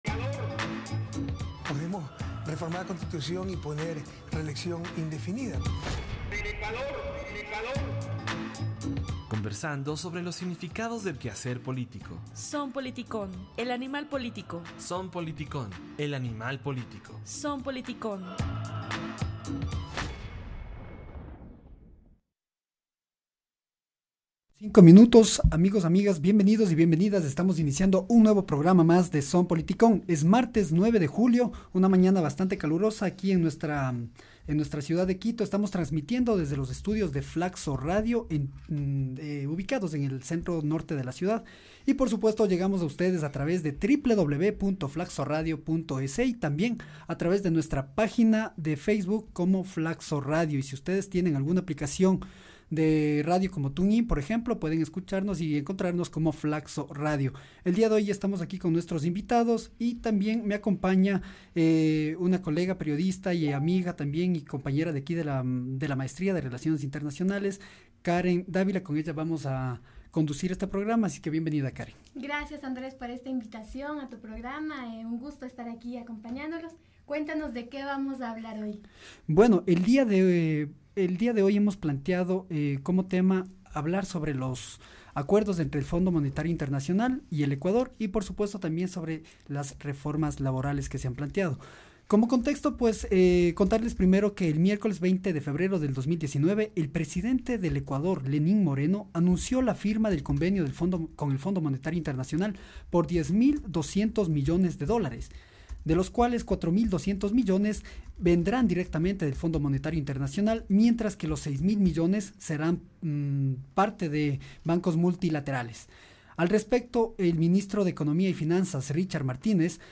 Audio - entrevista